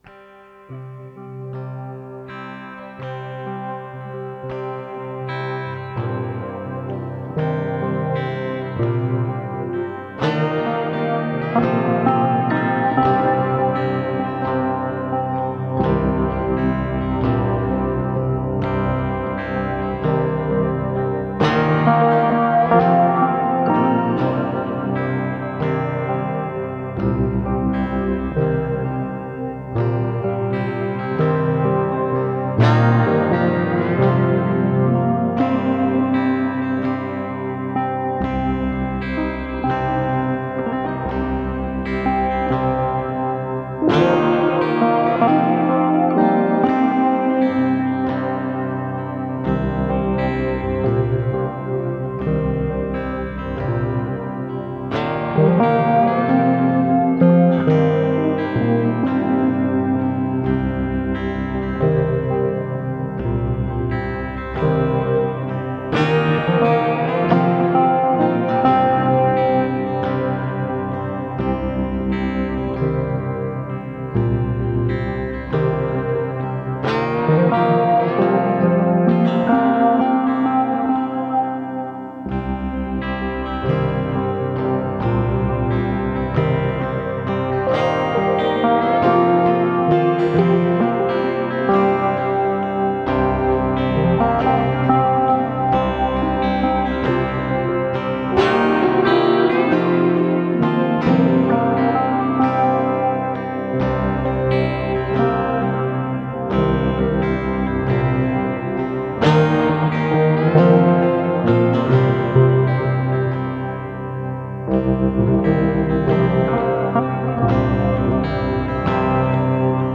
Peaceful instrumental amtosphere. Epiano with guitar.